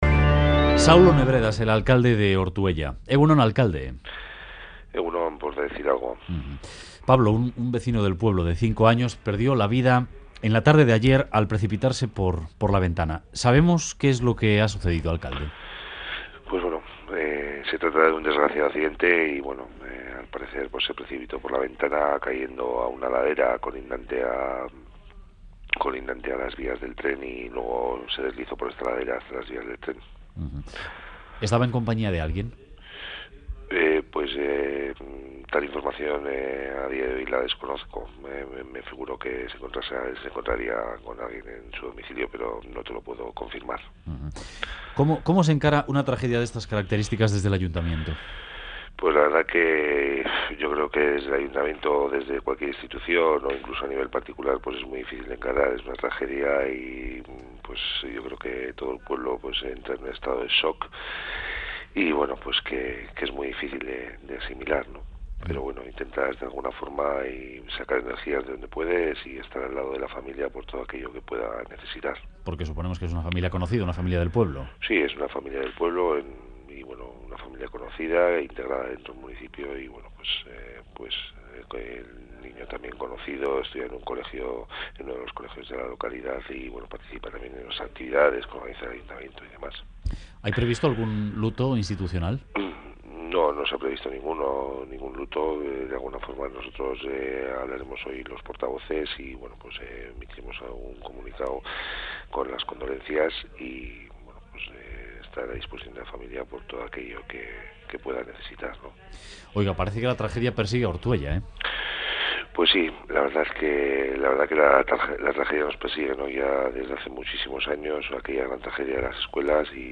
Ortuella : En Radio Euskadi, el alcalde de Ortuella califica de "desgraciado accidente" la muerte del niño de 5 años que cayó desde la ventana de su casa.